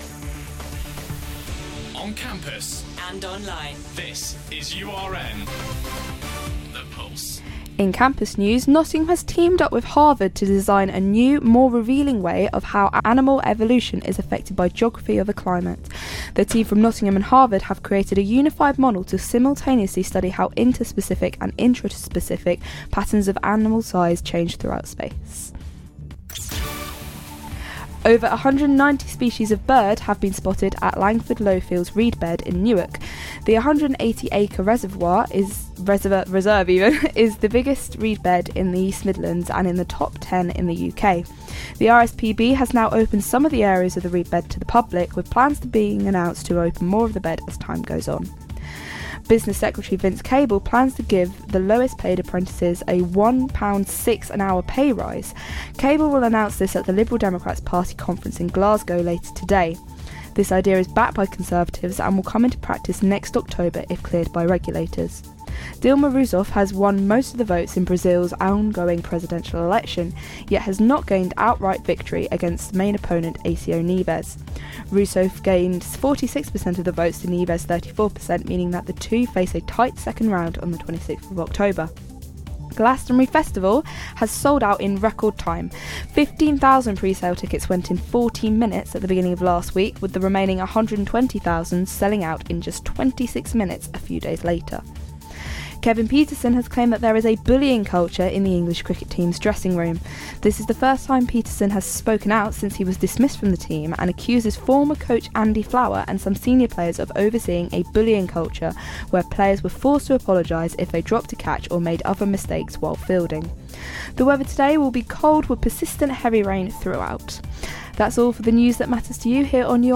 Your Latest Headlines - Monday 6th October